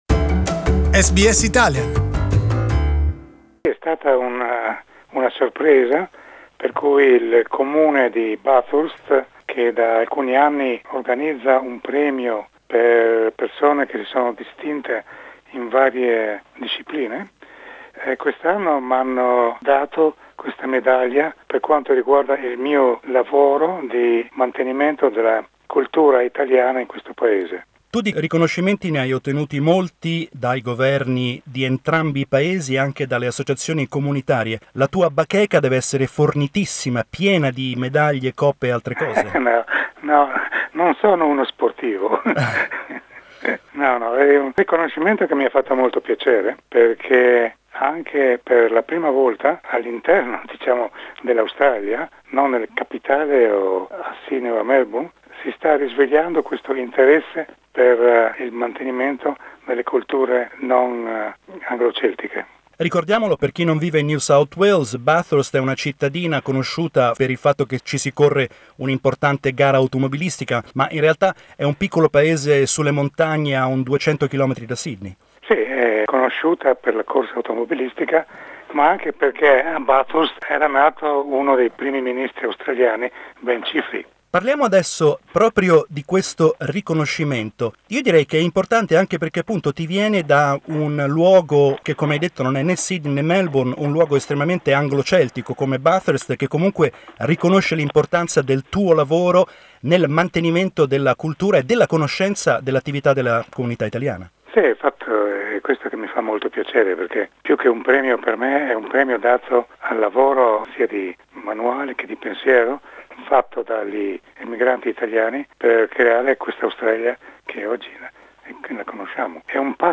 Gli abbiamo chiesto come abbia vissuto il conferimento di questa prestigiosa onorificenza.